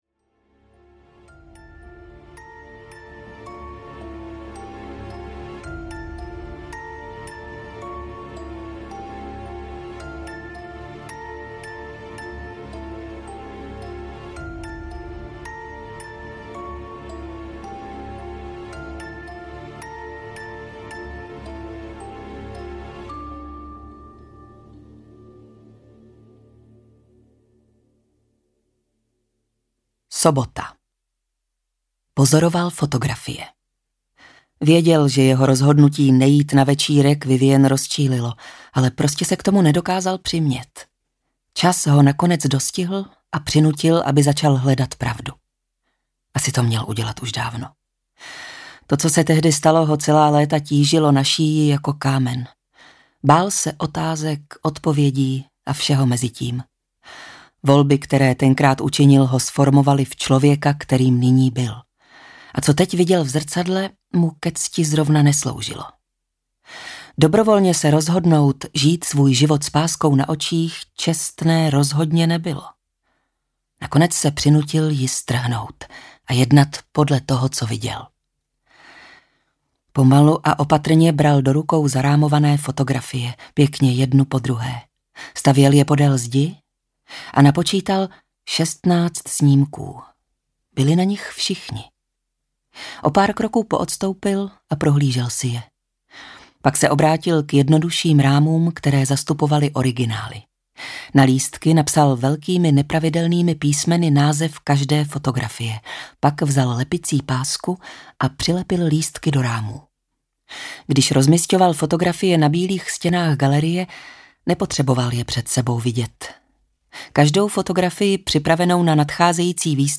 Kukačka audiokniha
Ukázka z knihy